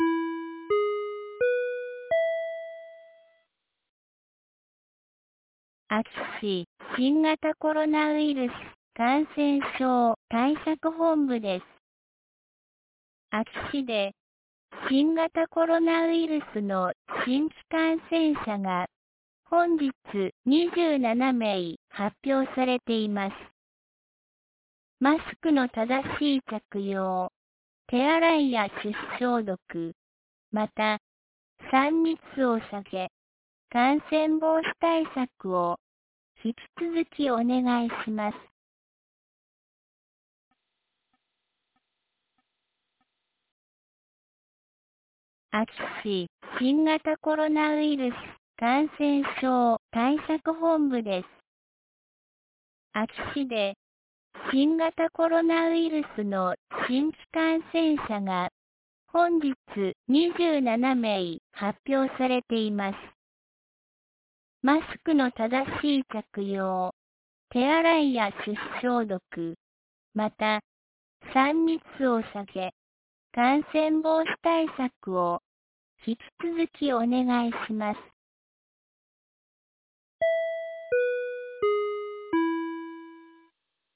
2022年08月20日 17時06分に、安芸市より全地区へ放送がありました。